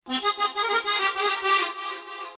Категория: Рингтон на SMS